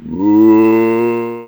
c_zombim2_hit1.wav